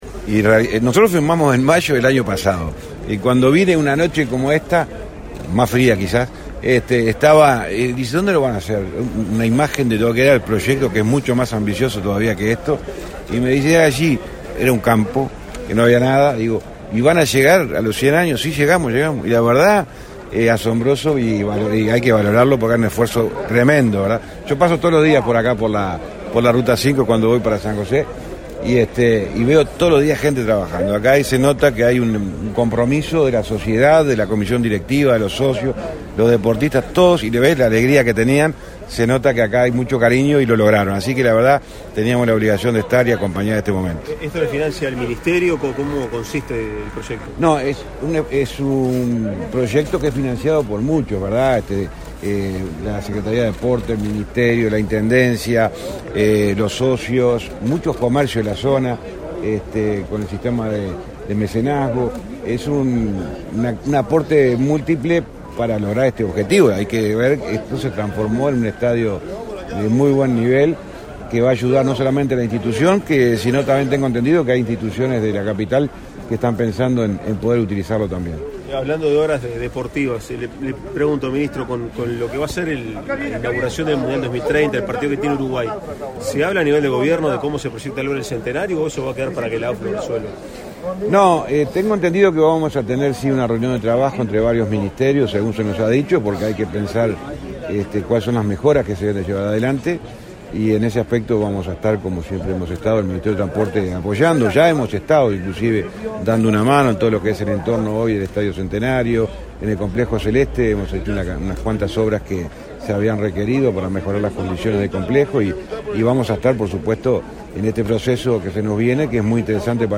Declaraciones a la prensa del ministro de Transporte y Obras Públicas, José Luis Falero
Declaraciones a la prensa del ministro de Transporte y Obras Públicas, José Luis Falero 12/10/2023 Compartir Facebook X Copiar enlace WhatsApp LinkedIn Tras participar en la inauguración del estadio Gloria de un Pueblo, del Club Atlético Juanicó, en Canelones, este 12 de octubre, el ministro de Transporte y Obras Públicas, José Luis Falero, realizó declaraciones a la prensa.